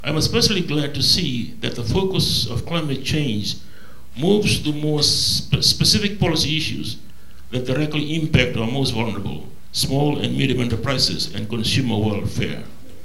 Acting Prime Minister Viliame Gavoka during the Fiji Institute of Chartered Accountants and the Asia-Pacific Applied Economics Association’s first ever international conference on climate accounting in Suva yesterday.